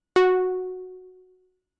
标签： midivelocity32 F4 midinote66 RolandJuno6 synthesizer singlenote multisample
声道立体声